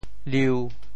潮州发音 潮州 liu1